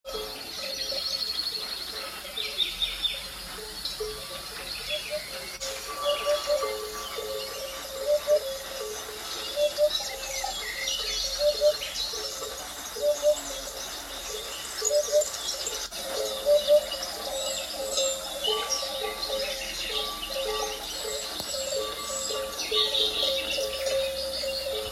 • Escolteu la puput?